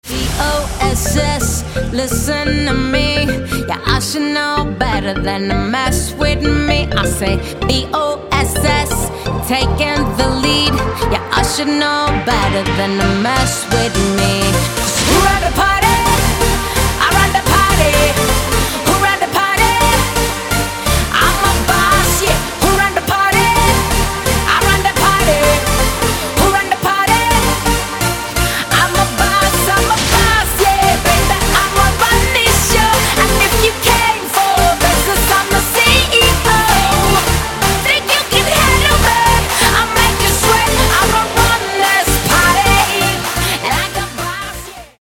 • Качество: 160, Stereo
Энергичный рингтон